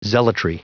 Prononciation du mot zealotry en anglais (fichier audio)
Prononciation du mot : zealotry